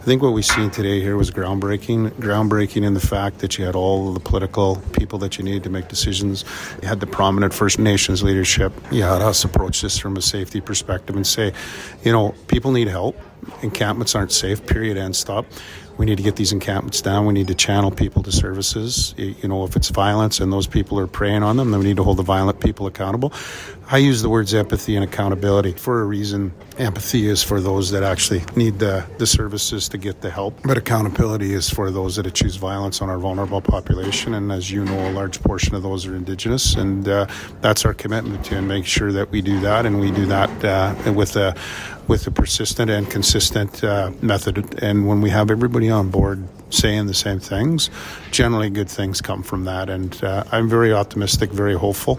Edmonton Police Service Chief Dale McFee spoke with Windspeaker Radio Network about the announcement, calling it a groundbreaking event, and aims towards assisting Indigenous members towards healing and supports within the city.